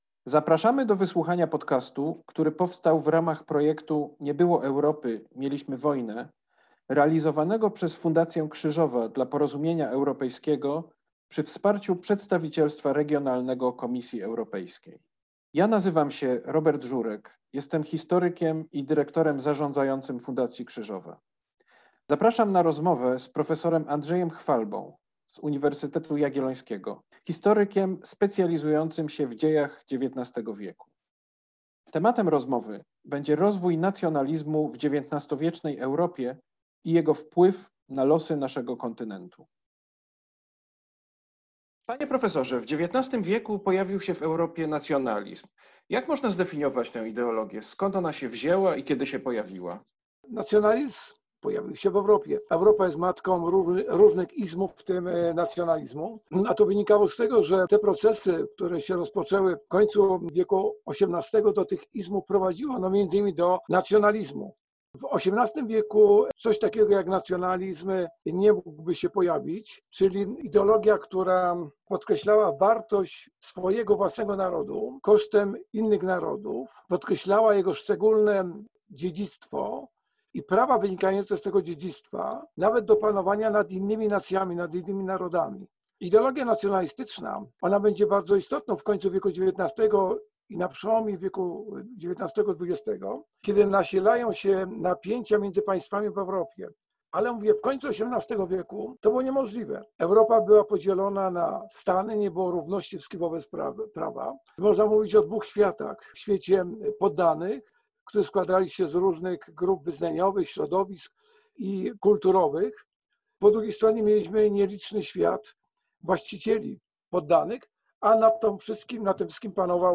Rozmowa z ekspertem